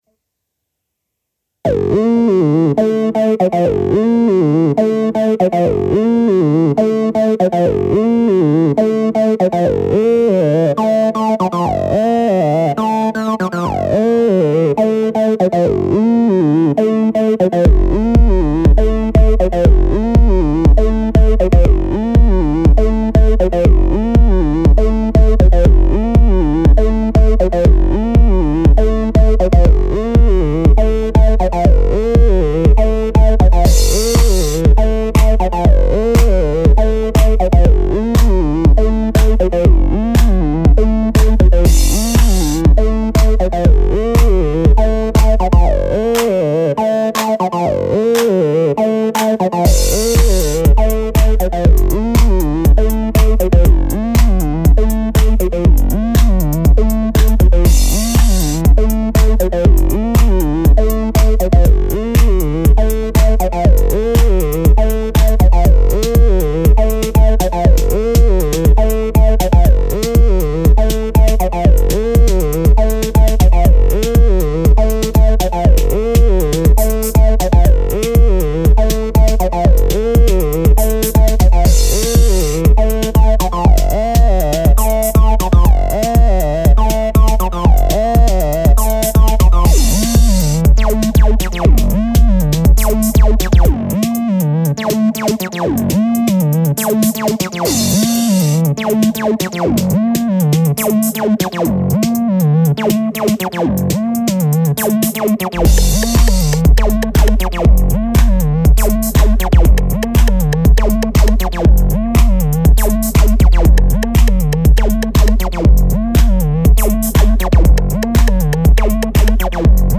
The present Masters thesis analyzes a series of five compositions based on early forms of house and techno music.